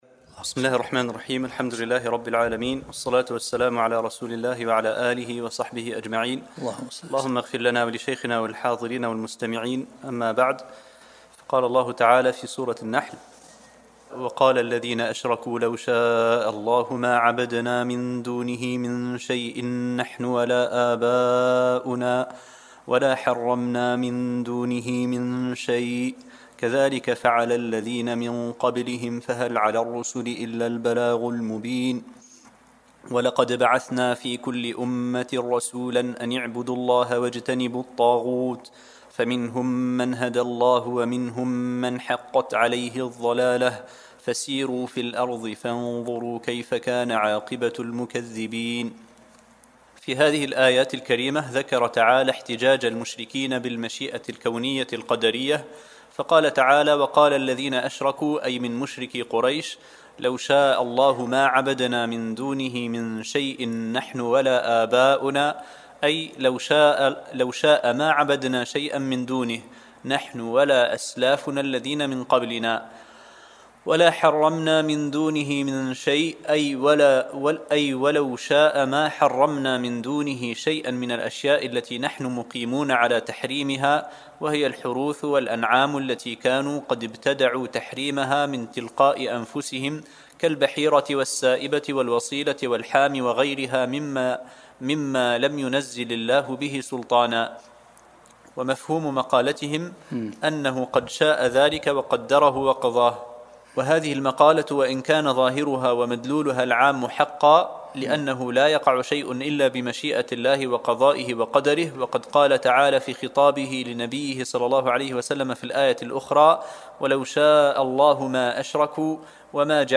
الدرس الخامس عشر من سورة النحل